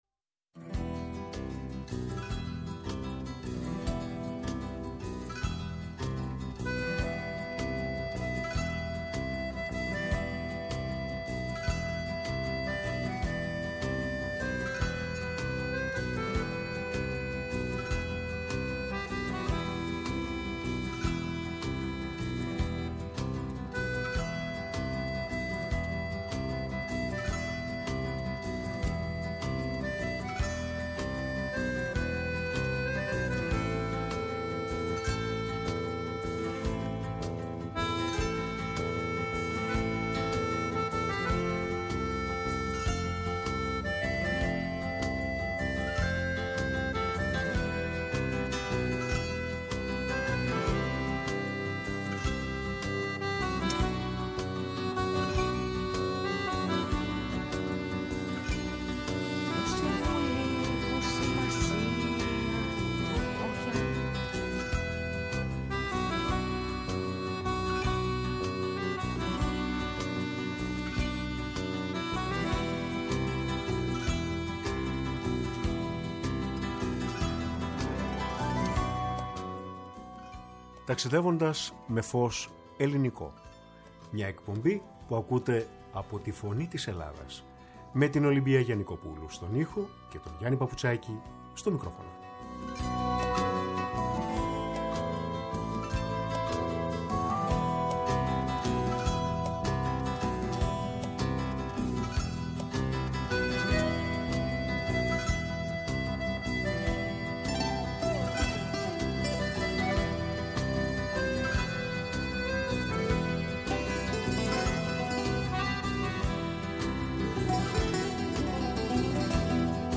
Η ΦΩΝΗ ΤΗΣ ΕΛΛΑΔΑΣ Ταξιδευοντας με Φως Ελληνικο ΜΟΥΣΙΚΗ Μουσική ΣΥΝΕΝΤΕΥΞΕΙΣ Συνεντεύξεις